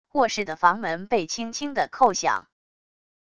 卧室的房门被轻轻的叩响wav音频